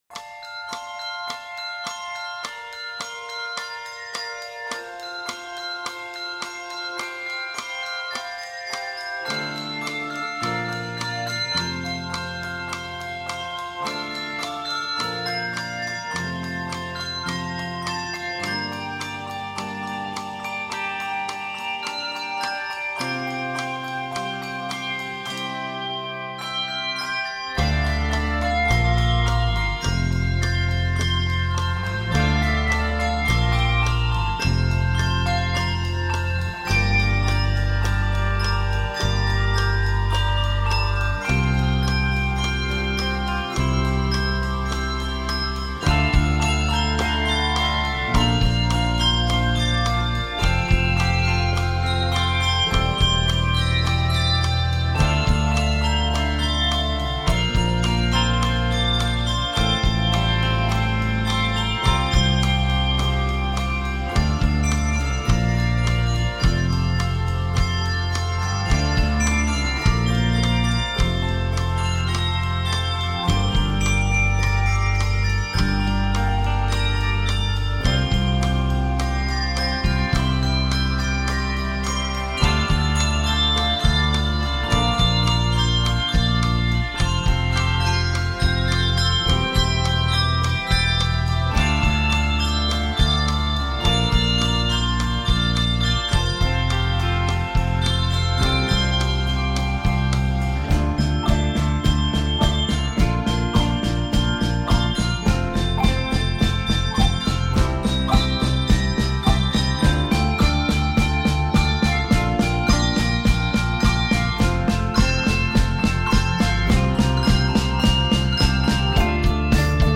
Driving rhythmic patterns dominate
energetic 3-6 octave arrangement